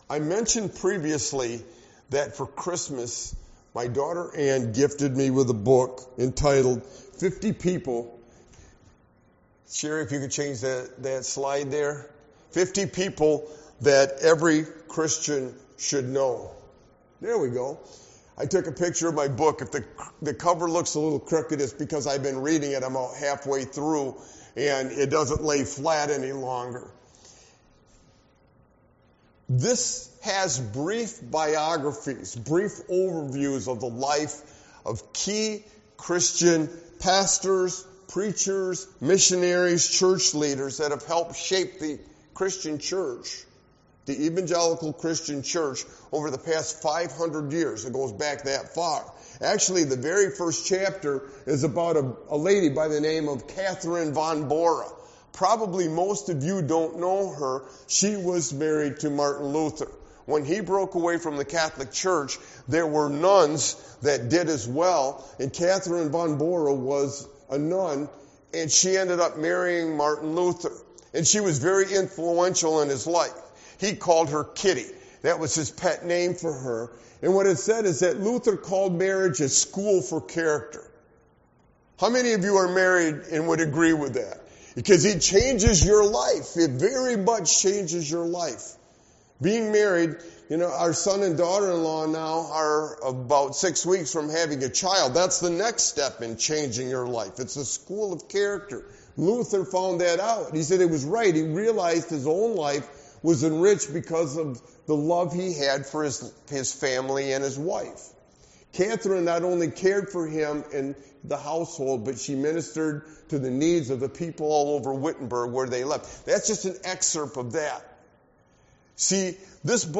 Sermon-Why-a-Sovereign-God-is-crucial-VIII-31322.mp3